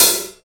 101 LOSE HAT.wav